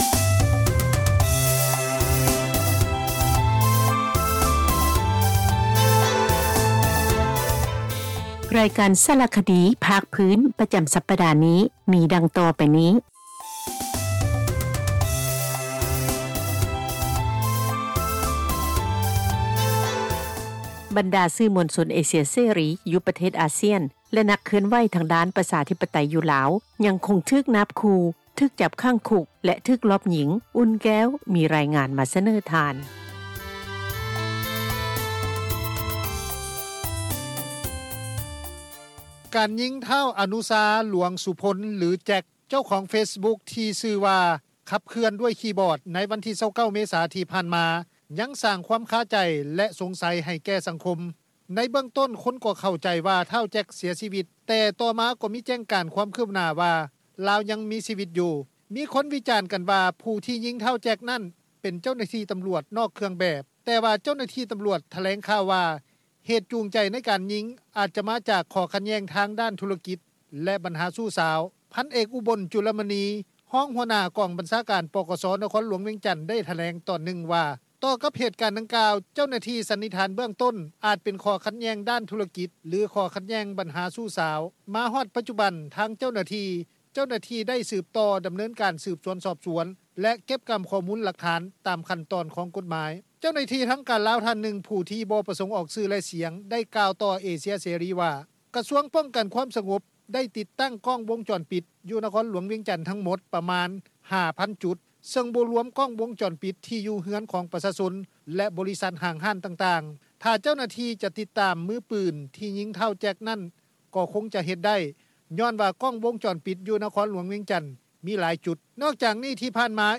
ສຽງນັກຂ່າວໂທສໍາພາດ: ນັກຂ່າວ: ຮາໂລ ທ່ານ.